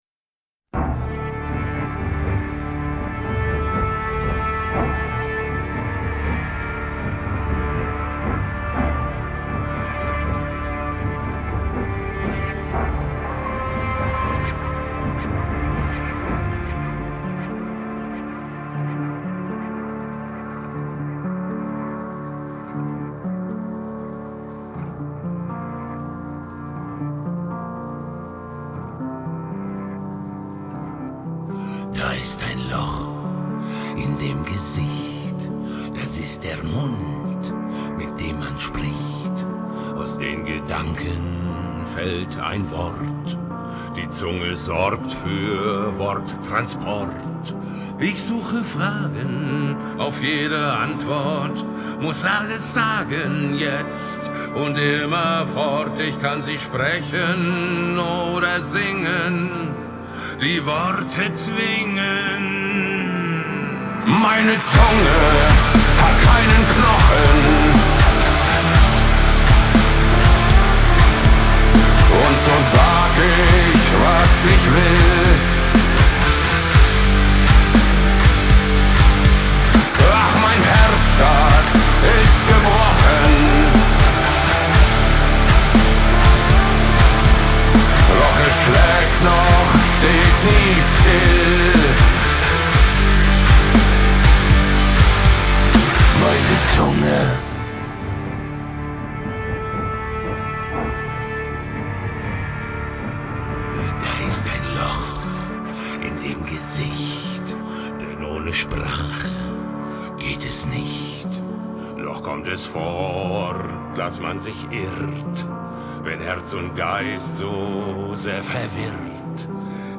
• Жанр: Metal